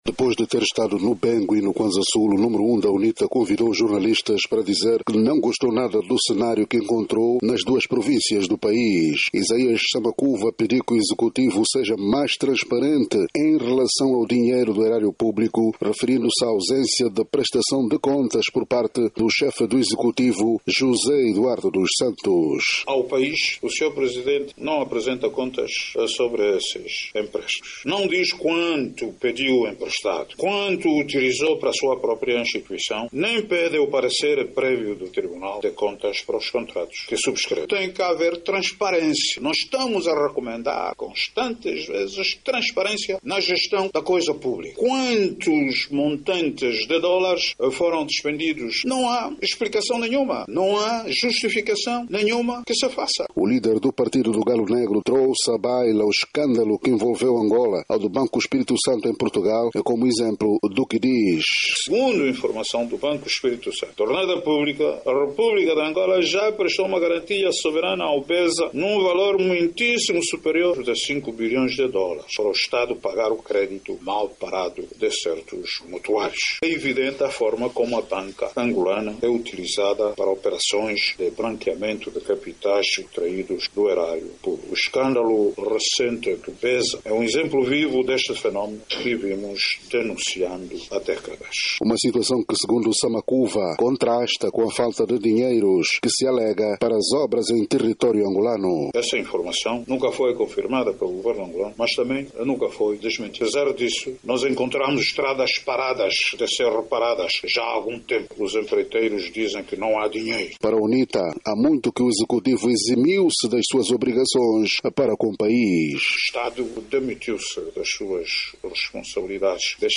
Conferência de imprensa de Samakuva - 2:41